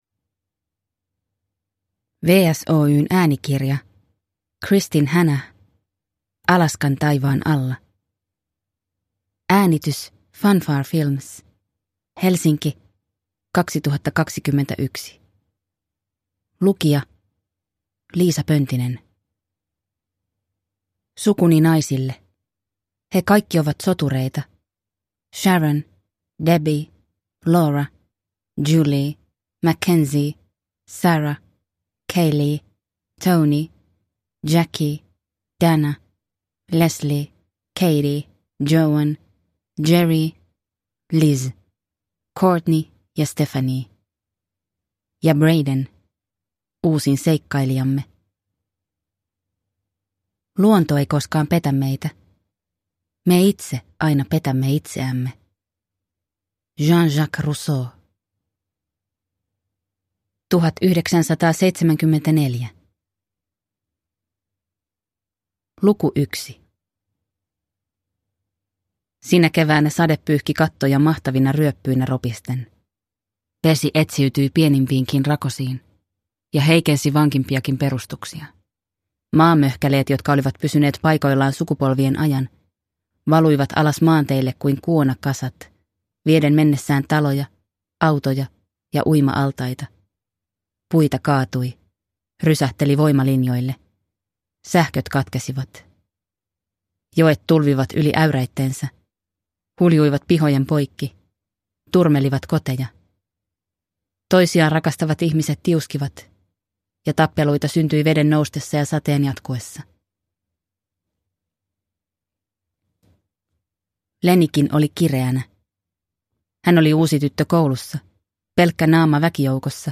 Alaskan taivaan alla – Ljudbok – Laddas ner